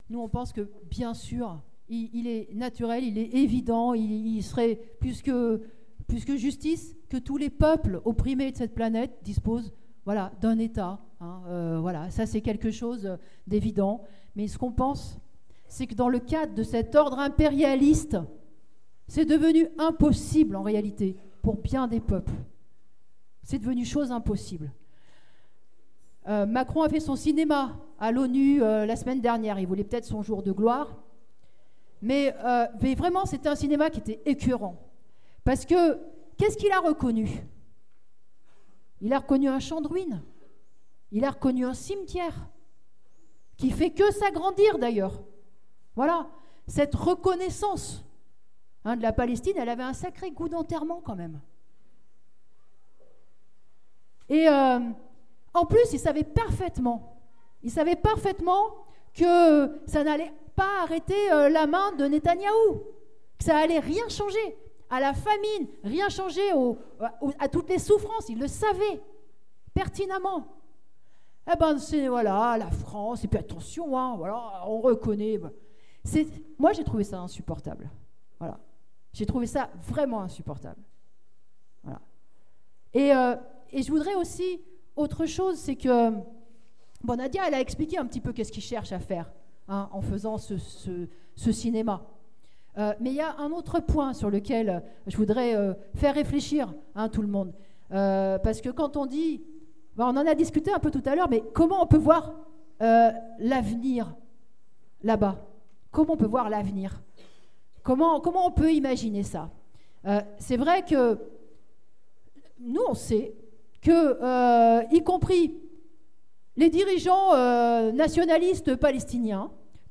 Nathalie Arthaud débat à la fête lyonnaise de LO : L'avenir est à une fédération socialiste des peuples du Moyen-orient